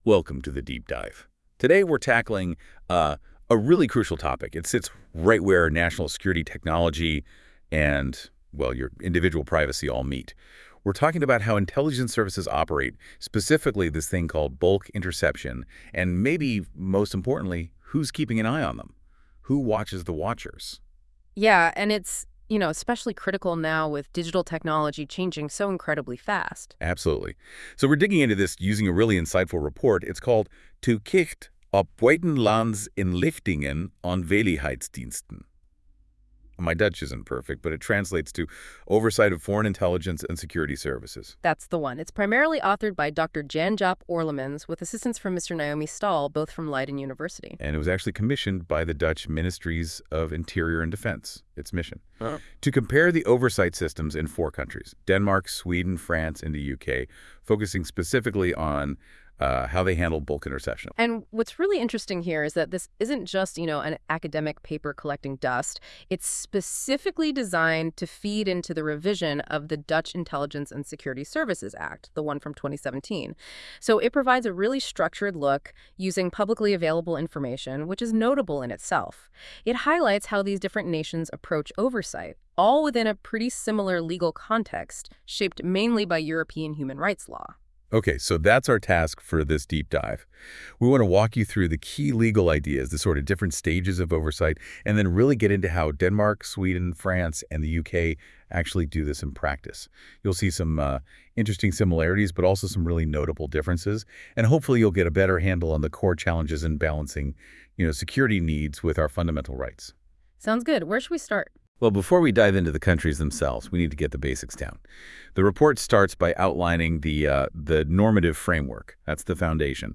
Luister eventueel ook naar de onderstaande (automatisch gegenereerde) podcasts of bekijk het rapport: